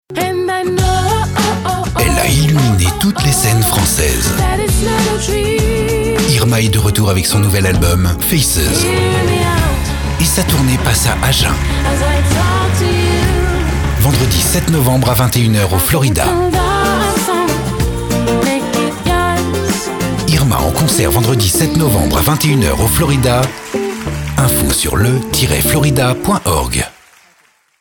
My voice is a cameleon voice.
Sprechprobe: Werbung (Muttersprache):